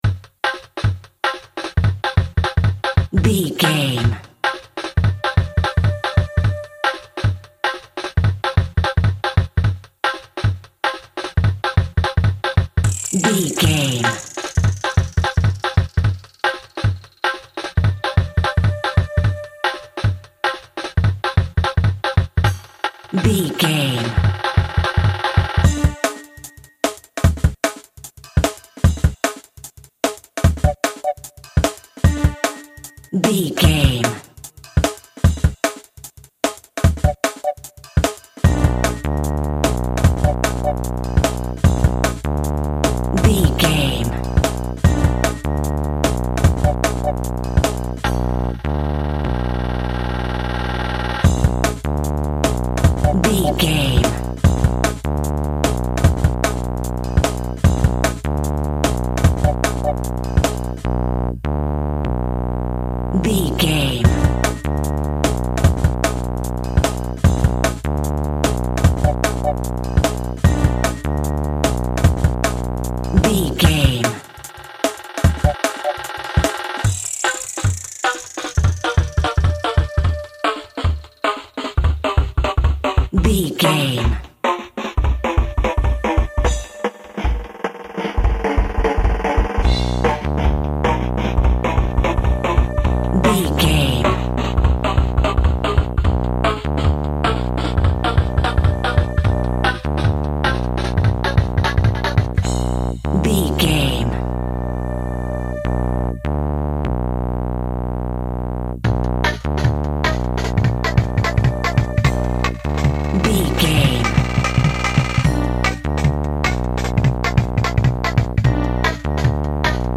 Standard Drum & Bass Music.
Aeolian/Minor
Fast
groovy
smooth
futuristic
frantic
drum machine
synthesiser
synth lead
synth bass